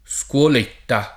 scuoletta [ S k U ol % tta ]